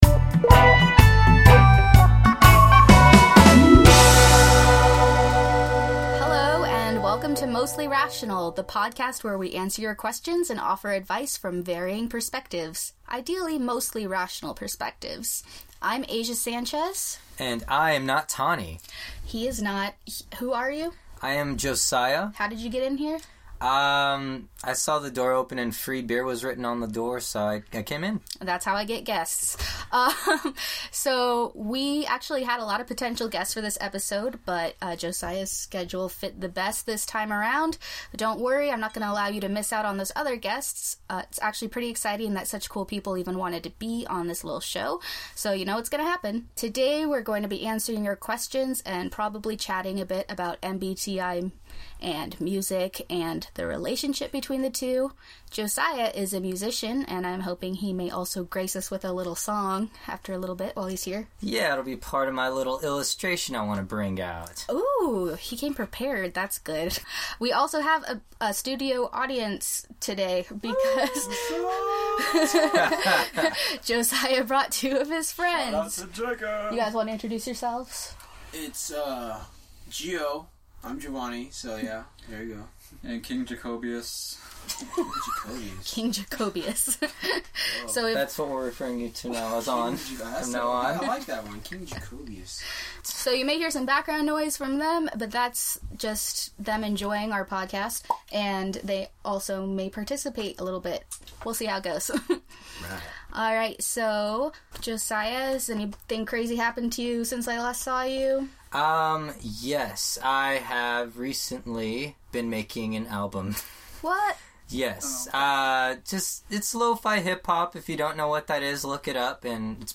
Episode 4: MBTI + Live Music